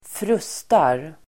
Ladda ner uttalet
Uttal: [²fr'us:tar]